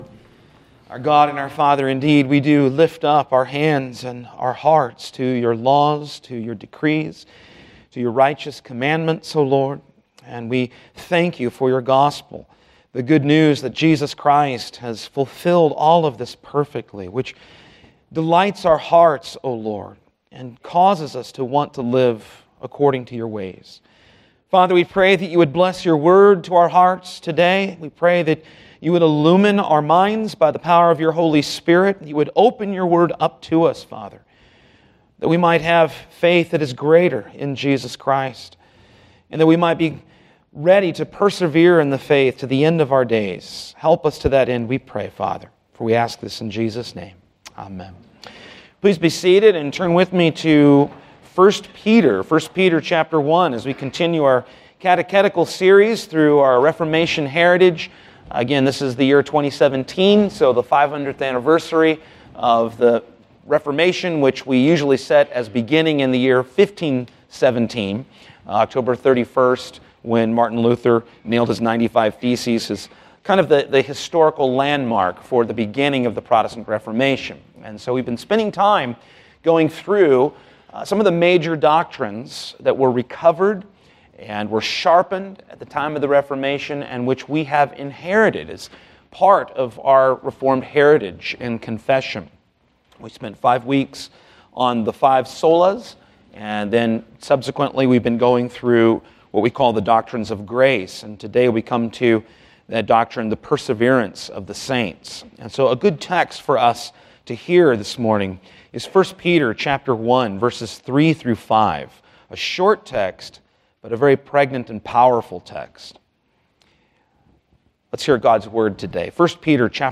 Sermons — Christ United Reformed Church